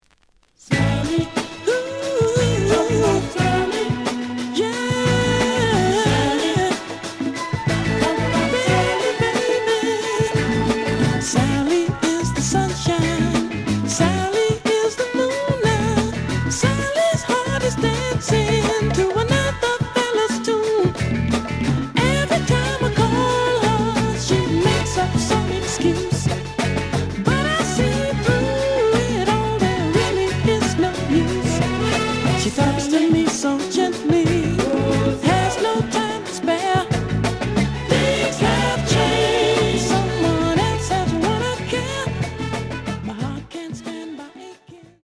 The audio sample is recorded from the actual item.
●Format: 7 inch
●Genre: Soul, 70's Soul
Slight edge warp.